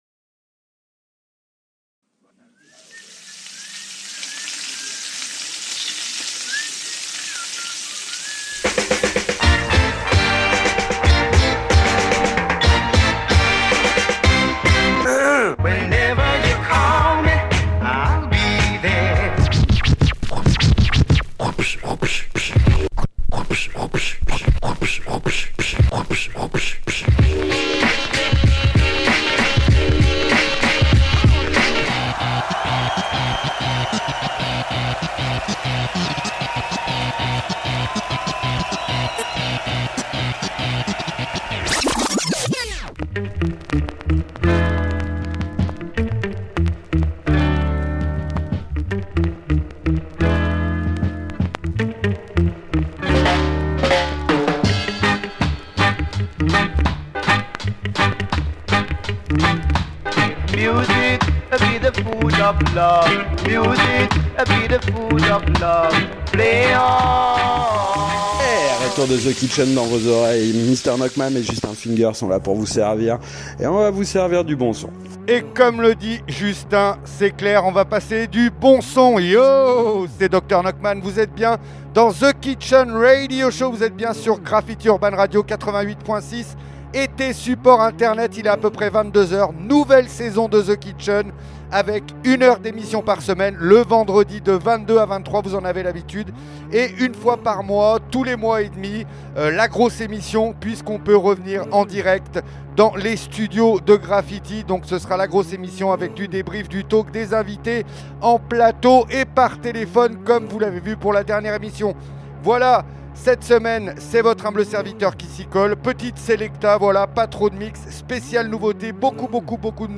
du mix , des interviews ,des découvertes du débat autour des musiques éléctro & hip-hop mais pas que .